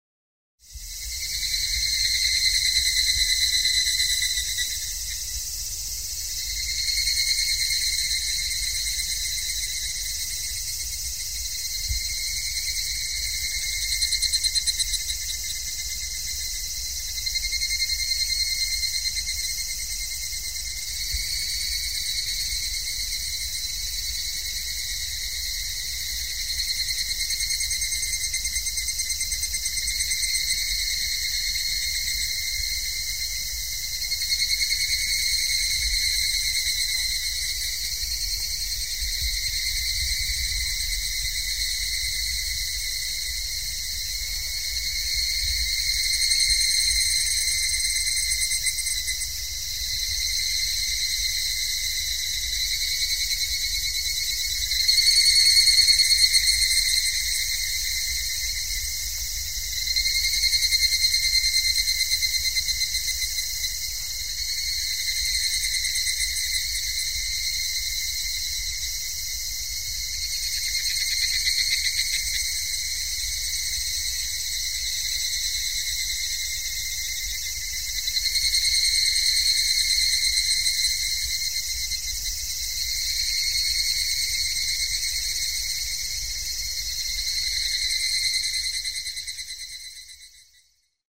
На этой странице собраны звуки цикад — от монотонного стрекотания до интенсивного хора насекомых.
Звук щебета цикады